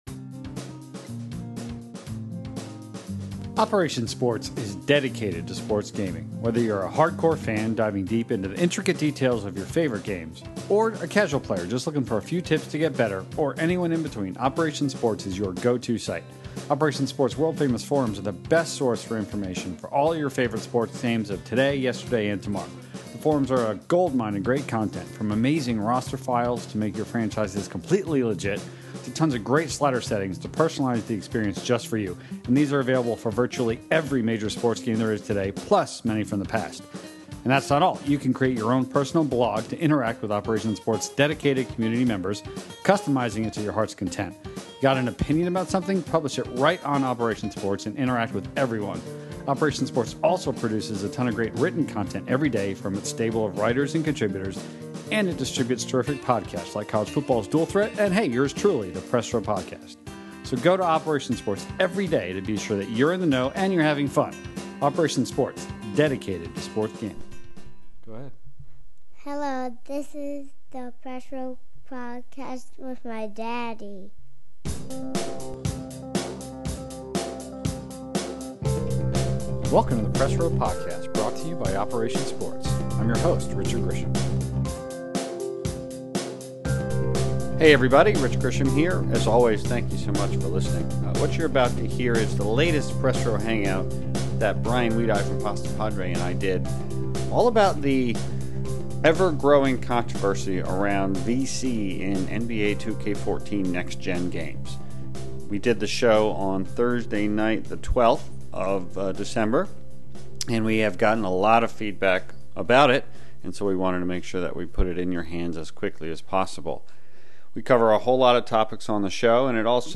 The 62nd episode of the Press Row Podcast is culled from a special Press Row Hangout dedicated to the discussion about the ever-growing controversy surrounding NBA 2K14 and its implementation of VC across many of the game modes of their next-gen versions.